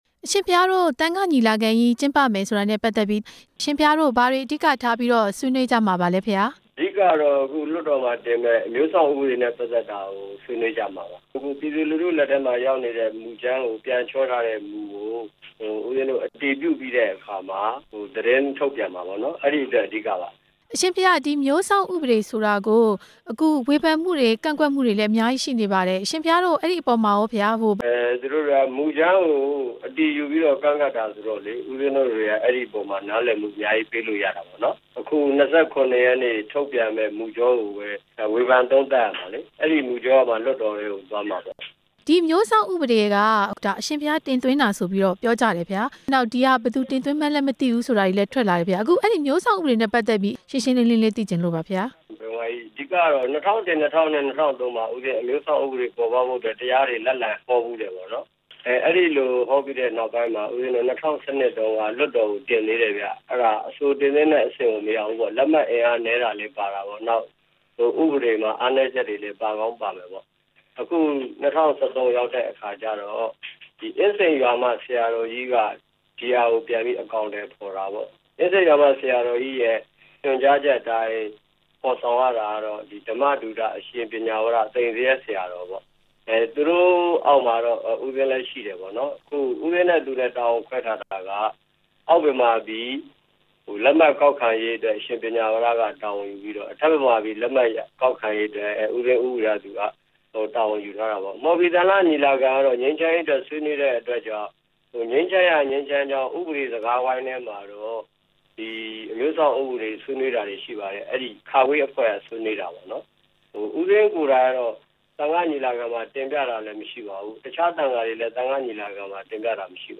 ဆရာတော် ဦးဝီရသူရဲ့ မိန့်ကြားချက်